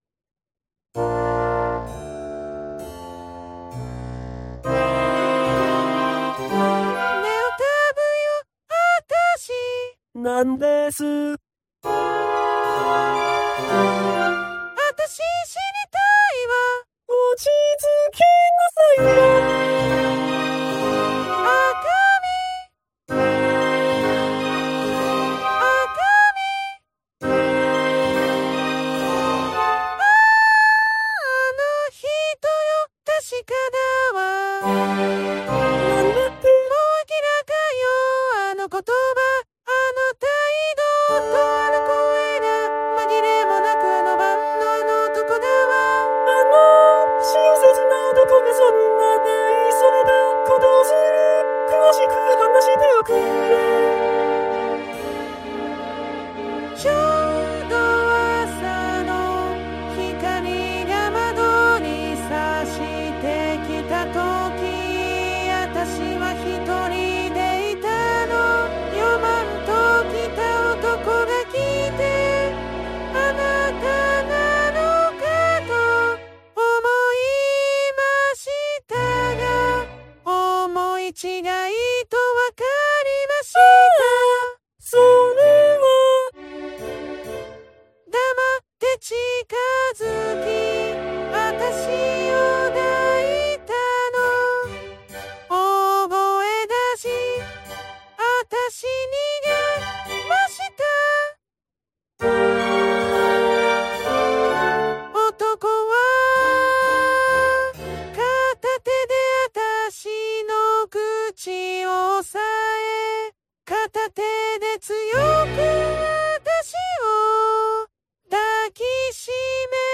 男声はGackpoidかKaitoを、女声は初音ミクNTを使いました。
管弦楽はGarritan Personal Orchestra5(VST)を使ってMP3形式で保存したものです。